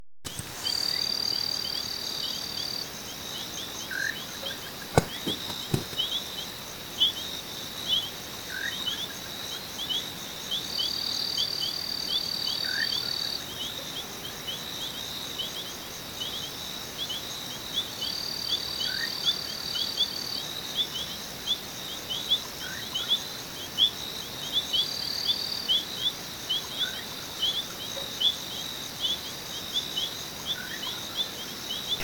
Atajacaminos Chico (Setopagis parvula)
Nombre en inglés: Little Nightjar
Localidad o área protegida: El Arañado
Condición: Silvestre
Certeza: Vocalización Grabada